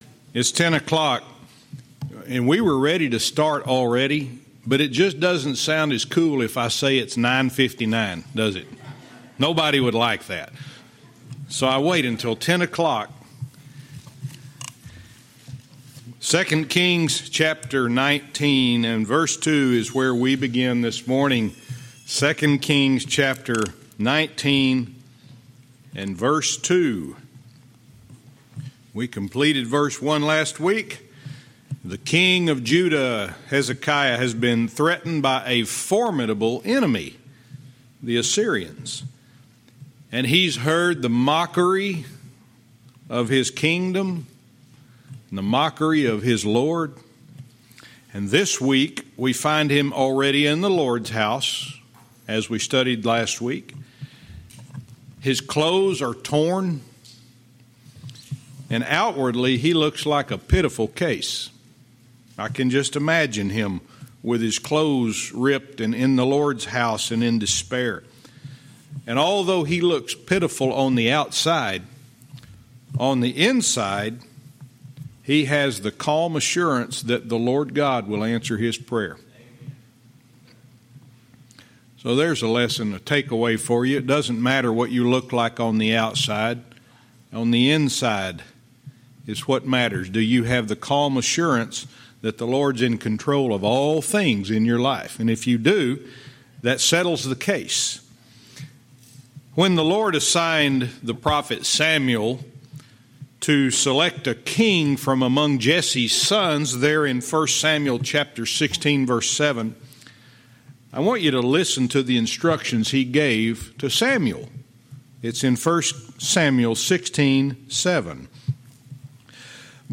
Verse by verse teaching - 2 Kings 19:2